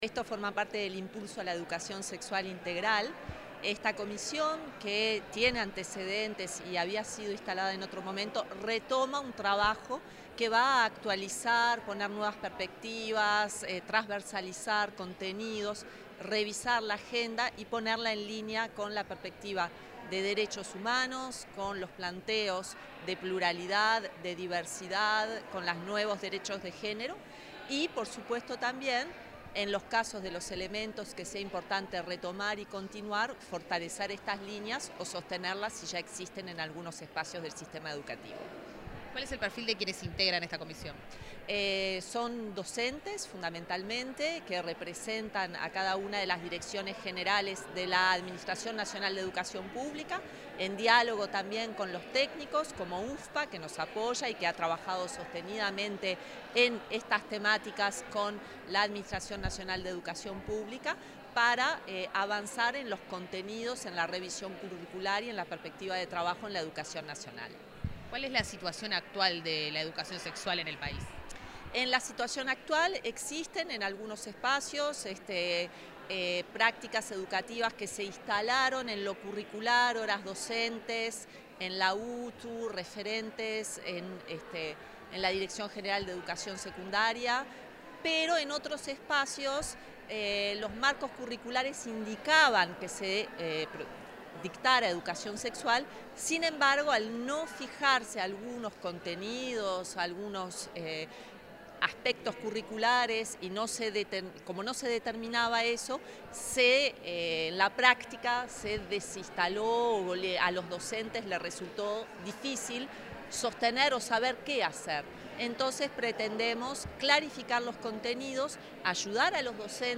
Declaraciones de la directora de Derechos Humanos, Nilia Viscardi